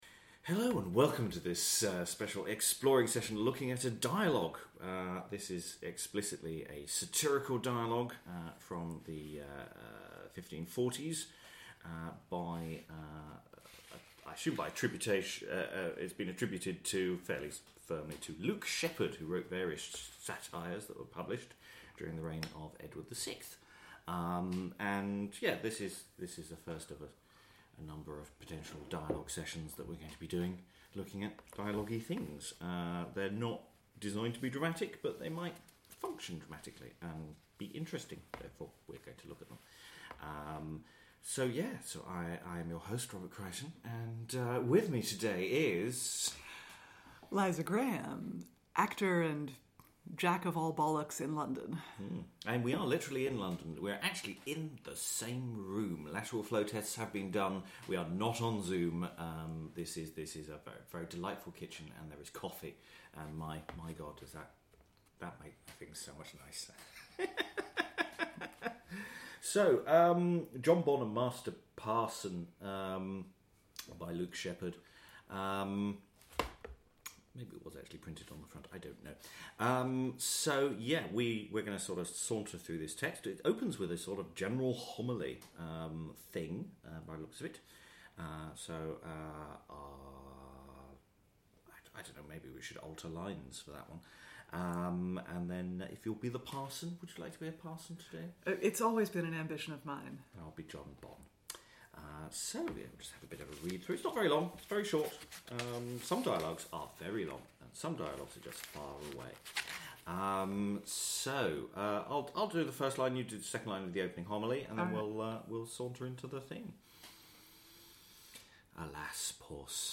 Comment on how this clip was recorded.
This is an exploring session recorded in London in the spring of 2022, and there will be a full audio production to follow.